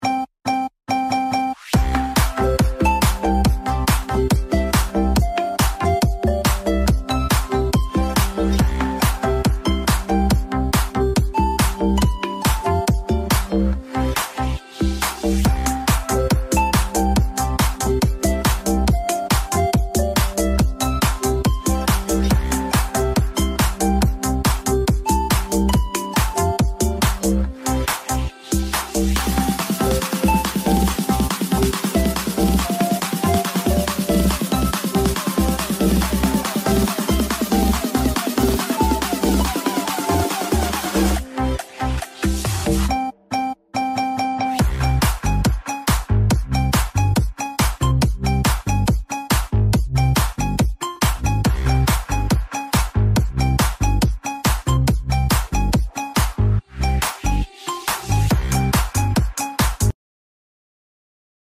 bass cực mạnh